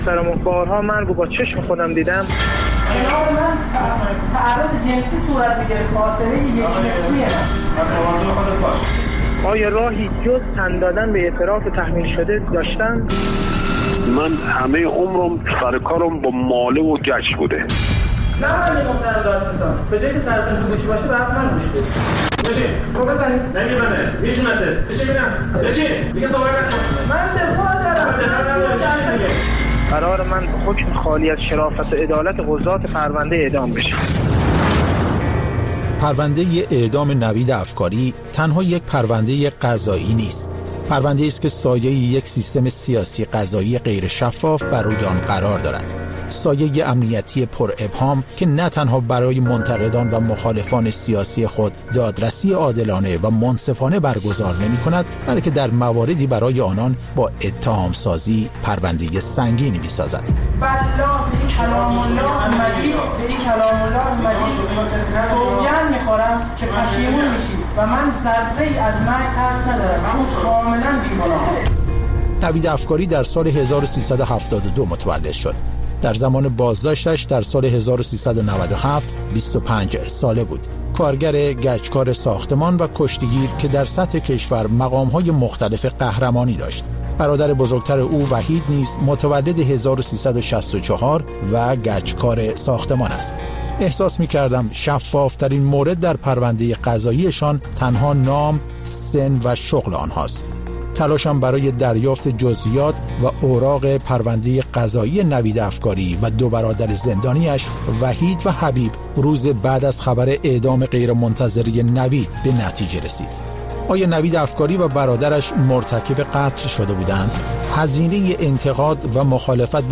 بازپخش مستند رادیویی: «پرونده نوید افکاری»